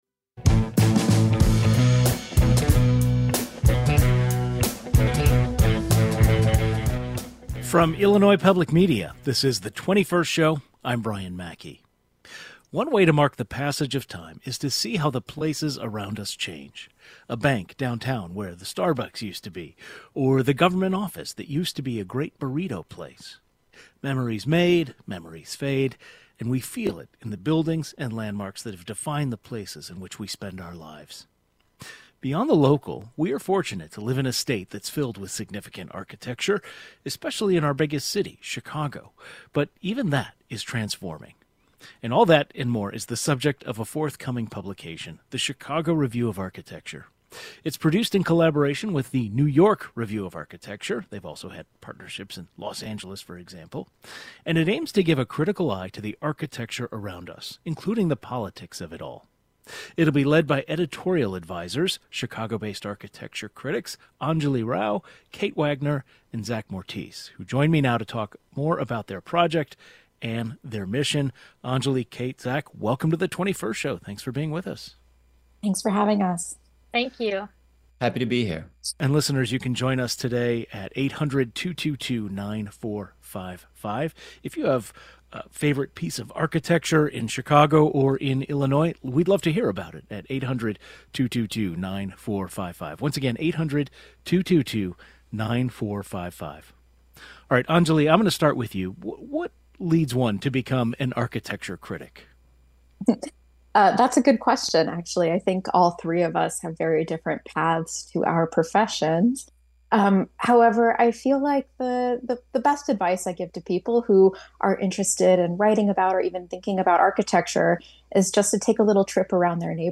They join the program to discuss their project and their mission.